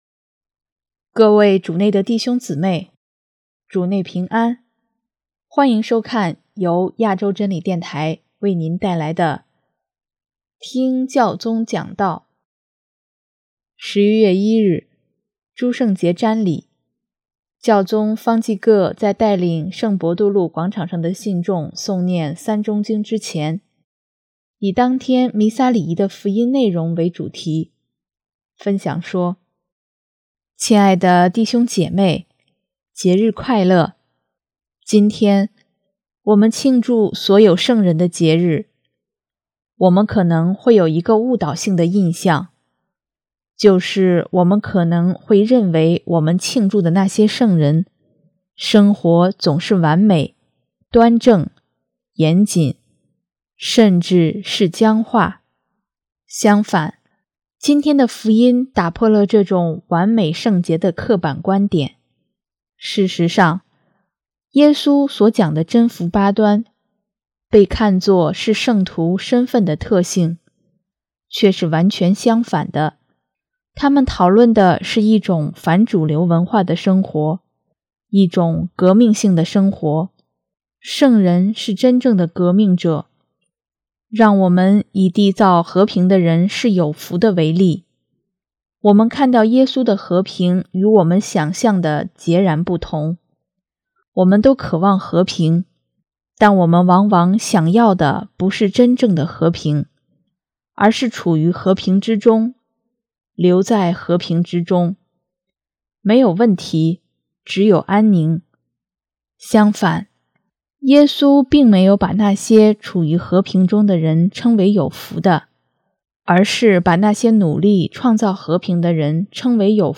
【听教宗讲道】|成为和平的缔造者
11月1日，诸圣节瞻礼，教宗方济各在带领圣伯多禄广场上的信众诵念《三钟经》之前，以当天弥撒礼仪的福音内容为主题，分享说：